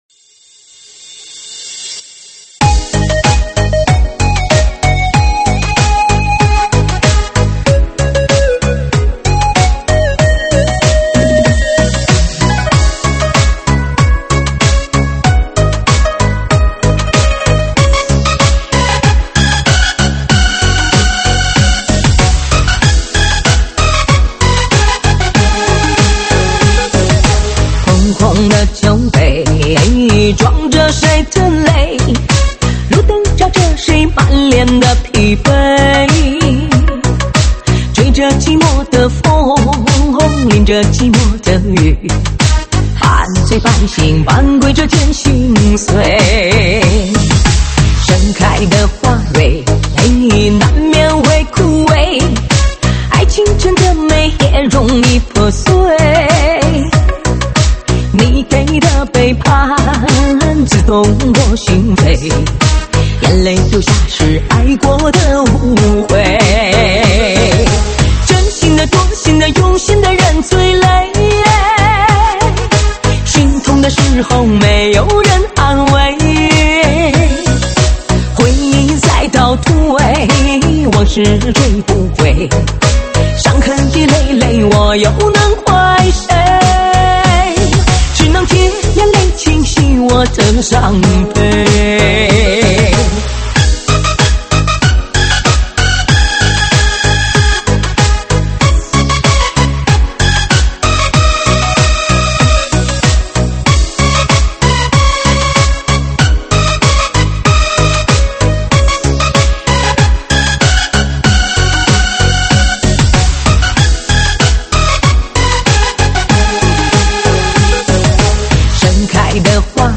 舞曲类别：中文慢摇